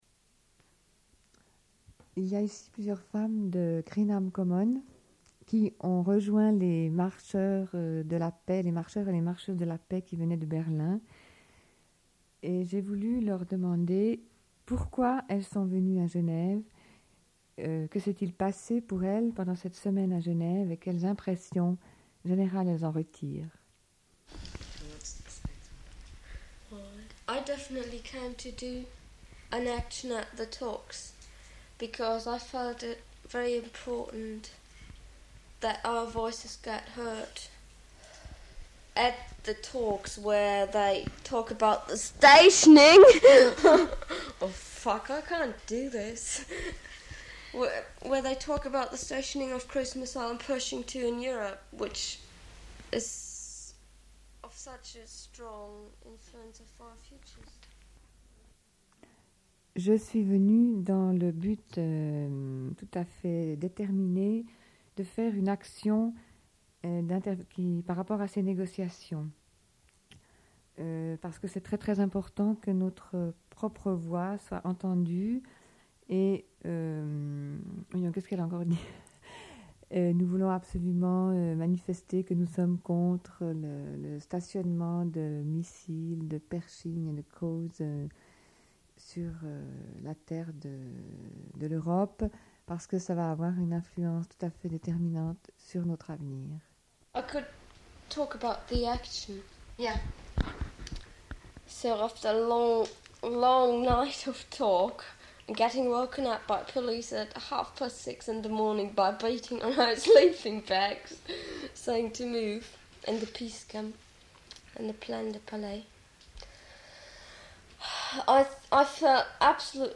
Une cassette audio, face A31:28
Rush de l'émission du 6 mars 1984. Discussion avec des femmes de Greenham Common, présentes à Genève à l'occasion de l'arrivée des marcheur·euse·s de la paix arrivants de Berlin.